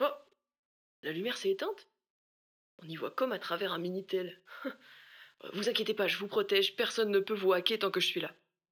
VO_LVL3_EVENT_Lumiere OFF_03.ogg